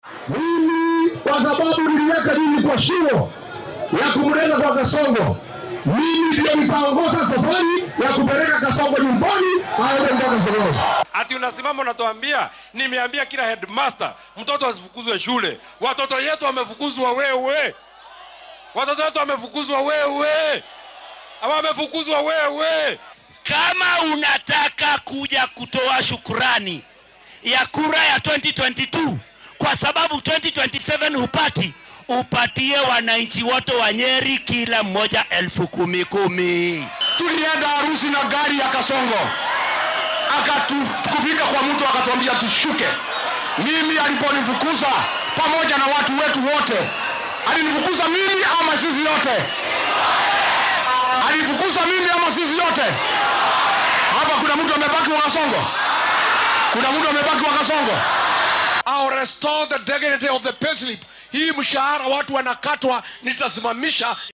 Hoggaamiyayaal siyaasadeed oo xulafo la ah madaxweyne ku xigeenkii hore ee dalka Rigathi Gachagua ayaa taageeray hoggaamiyaha xisbiga Democracy for Citizens Party (DCP) inuu noqdo musharraxa madaxtinimo ee mucaaradka ee doorashada guud ee 2027. Gachagua iyo xulafadiisa, oo khudbado ka jeediyay laba isu soo bax oo ka dhacay gobolka bartamaha dalka, ayaa si adag u dhaliilay dowladda, iyaga oo ku eedeeyay hirgelinta siyaasado ay ku tilmaameen kuwo aan dadka jeclayn isla markaana dib u dhac ku ah horumarka.